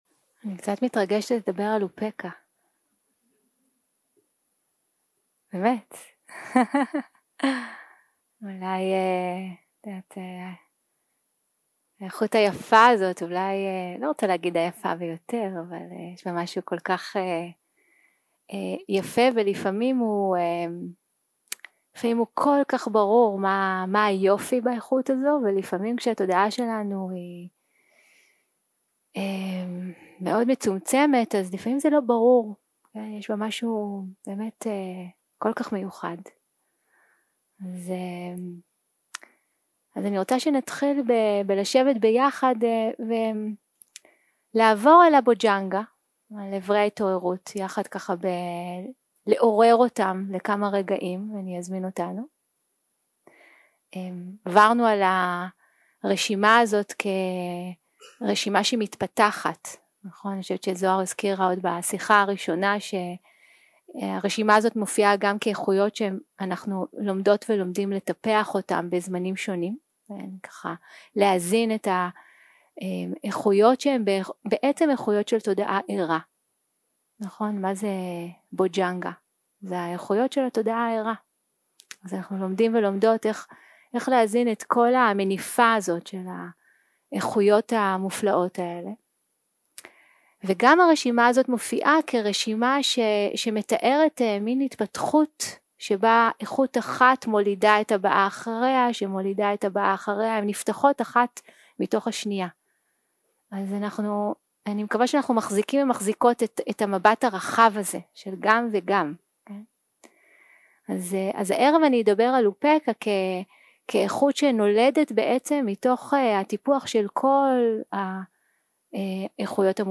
יום 6 - הקלטה 14 - ערב - שיחת דהרמה - אופקהא - ראייה צלולה
Dharma type: Dharma Talks שפת ההקלטה